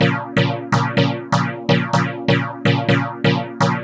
cch_synth_loop_motor_125_Bb.wav